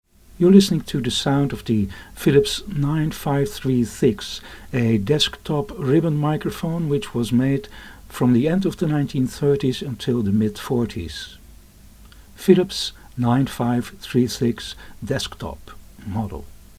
PHILIPS 9536 DESK TYPE RIBBON
The table model was advertised as 'of the highest quality', 'specially developed for speech' and 'where a microphone stand is not necessary'.